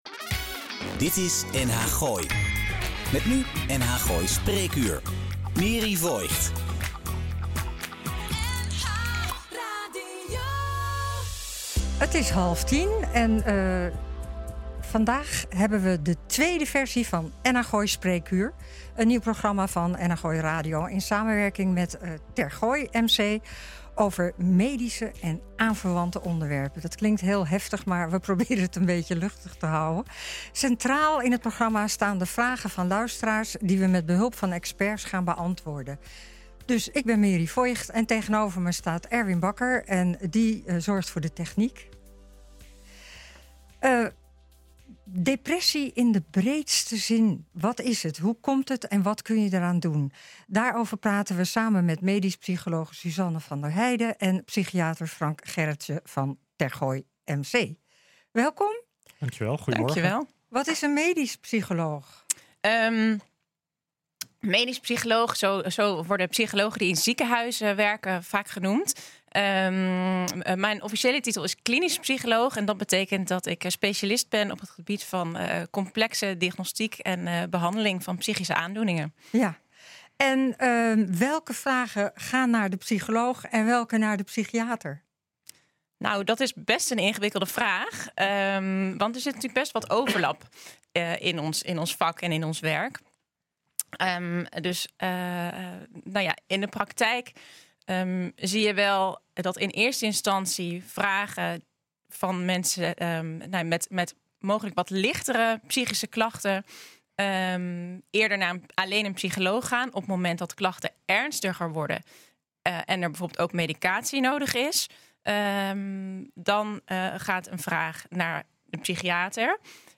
NH Gooi is de streekomroep voor Hilversum, Huizen, Blaricum, Eemnes en Laren.